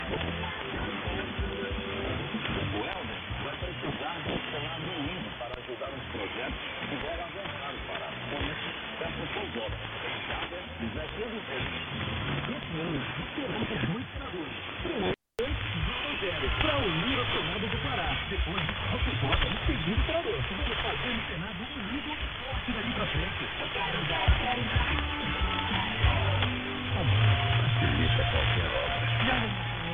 Für den Vergleich habe ich Audioaufnahmen der beiden Empfänger gemacht.
Sekunde 0-15 >> SDRplay RSPduo
Sekunde 15-30 >> Winradio G33DDC Excalibur Pro
RSPduo-G33DDC-Radio-Clube-do-Para-7khz-SAM-7khz-BW.mp3